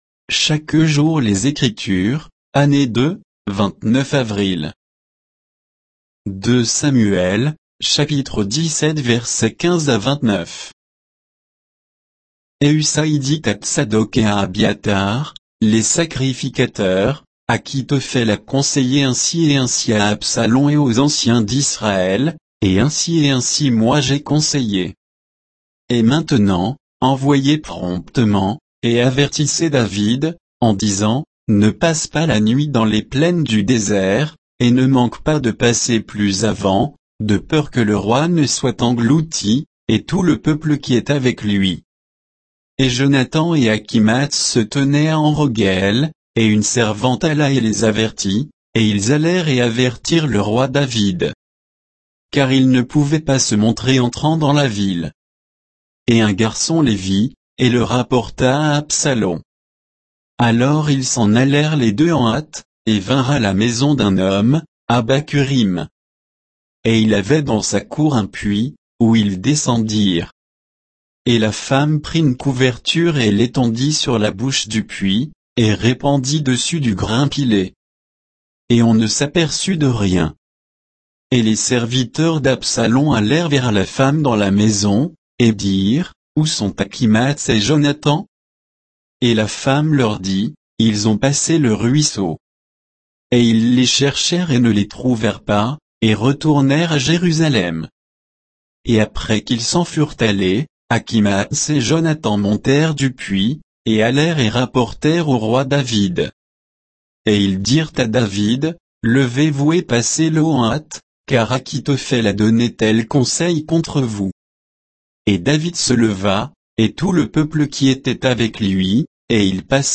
Méditation quoditienne de Chaque jour les Écritures sur 2 Samuel 17, 15 à 29